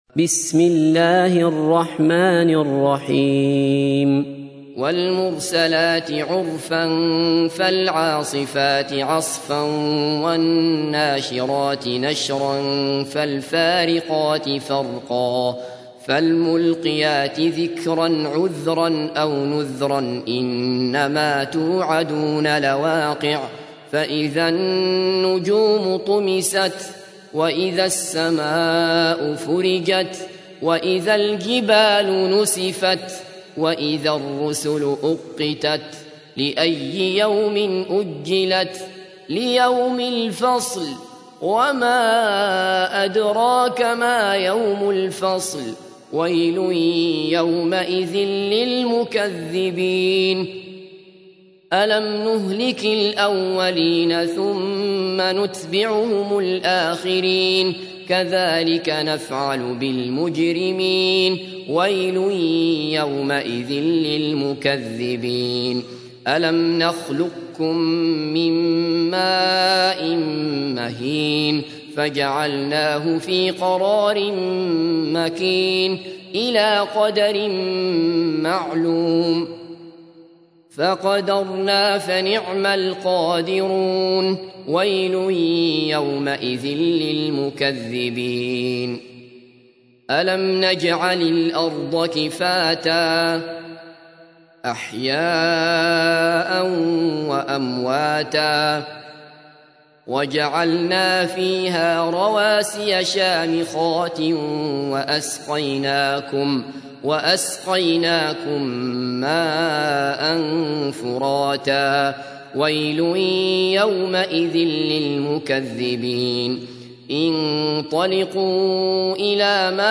تحميل : 77. سورة المرسلات / القارئ عبد الله بصفر / القرآن الكريم / موقع يا حسين